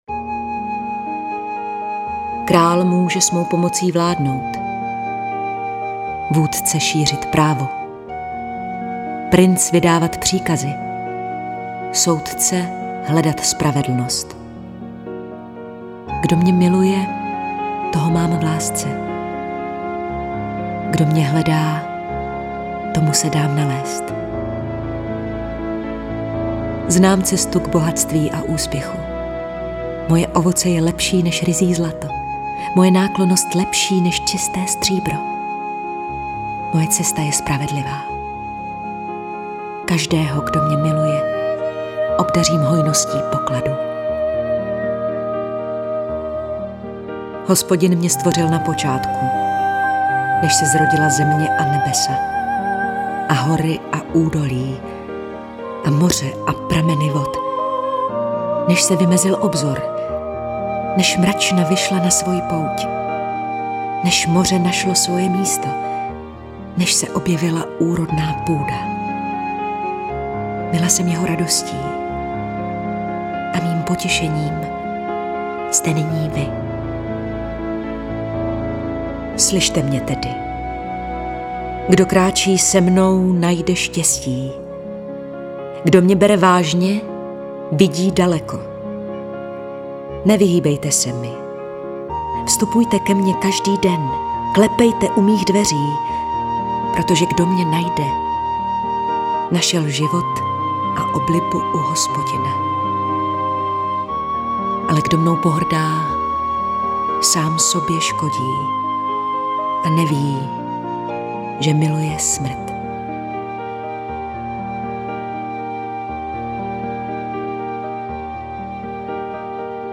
Nahrávka vznikla na podzim roku 2015 ve studiích DAMU, postprodukce následovala v zimě 2016 ve studiu BITT.
Herci, kteří v nahrávce vystupují: